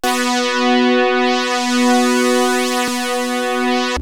JUP.8 B4   2.wav